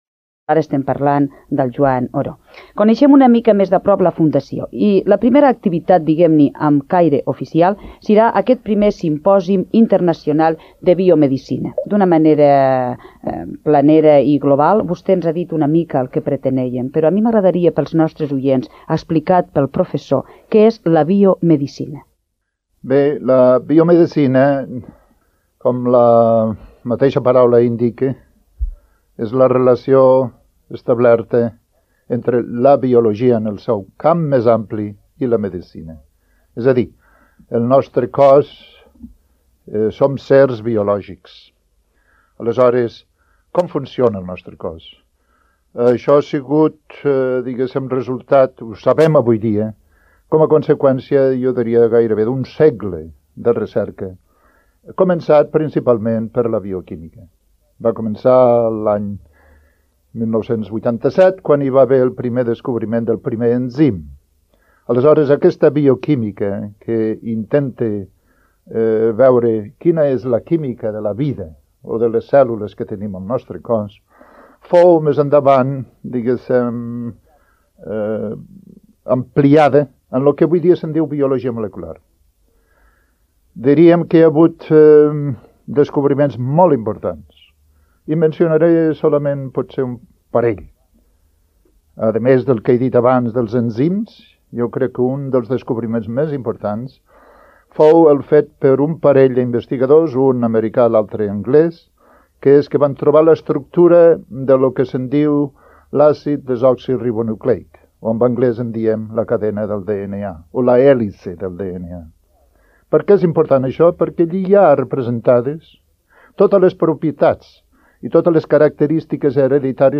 Entrevista Joan Oró - Antena 3 Lleida, 1993
Audios: arxius sonors d’emissores diverses